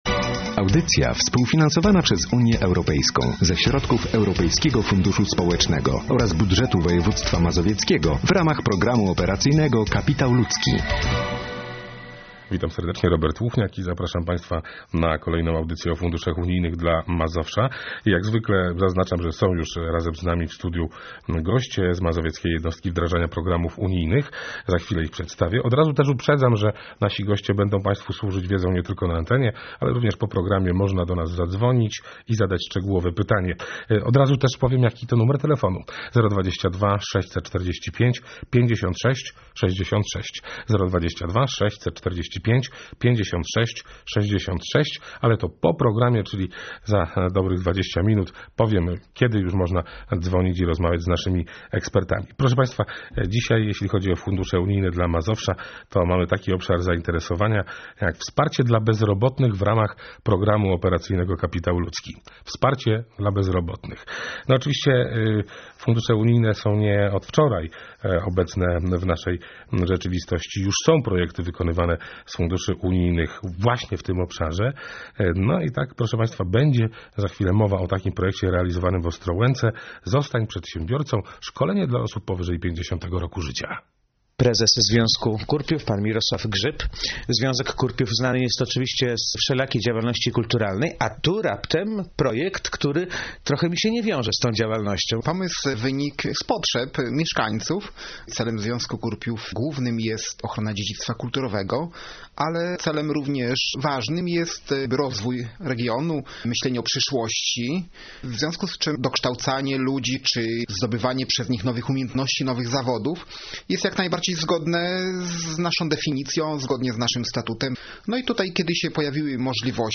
W piątek 5 grudnia 2008 r. wyemitowana została audycja poświęcona Wsparciu dla bezrobotnych w ramach Programu Operacyjnego Kapitał Ludzki.